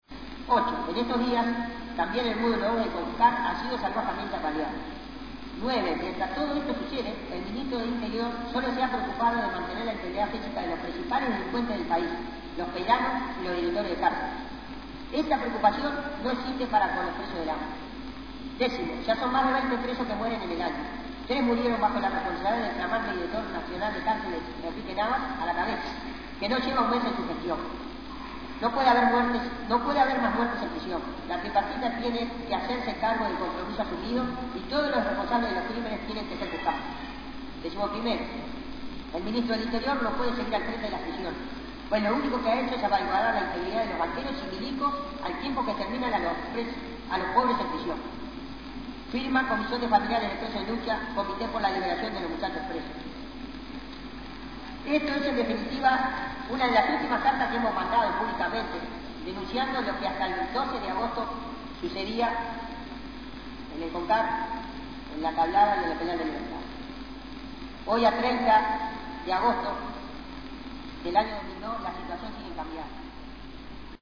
Comenzó leyendo un comunicado emitido por Familiares de Presos en Lucha y el Comité por la Libertad de los Muchachos Presos. Concluyó desarrollando ampliamente la situación que viven los presos sociales en los campos de concentración, especialmente en las ruinas del Penal de «Libertad».